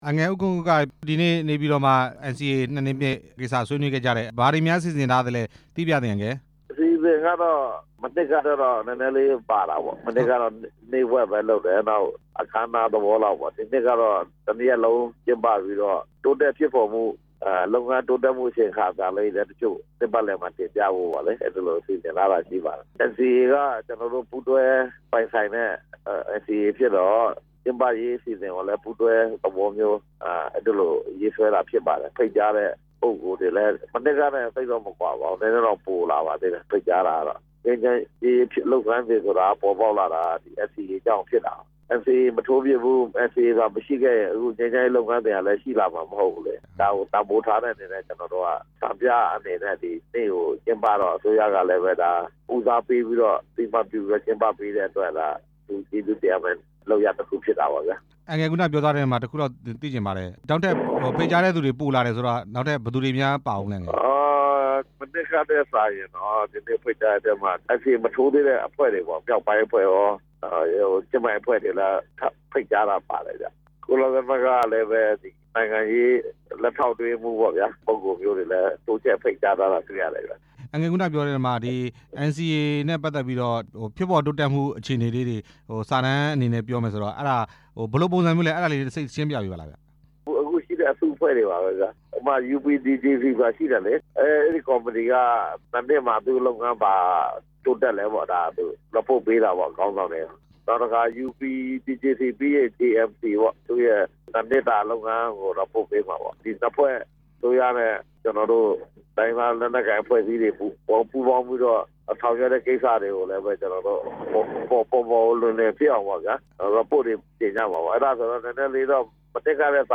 NCA ၂နှစ်ပြည့် အခမ်းအနား ကျင်းပမယ့်အကြောင်း မေးမြန်းချက်